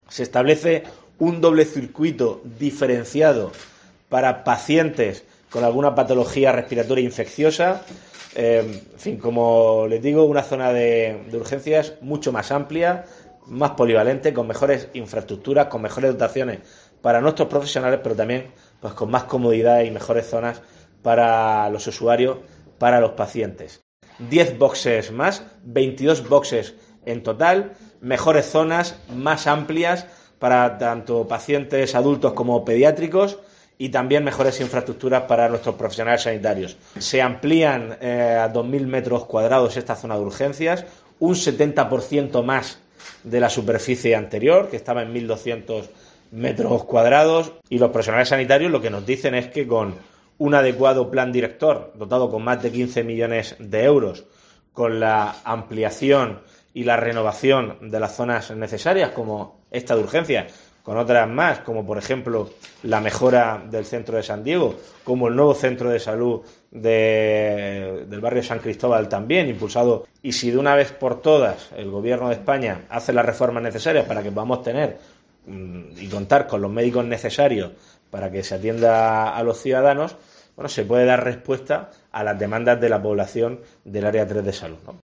Fernando López Miras, presidente CARM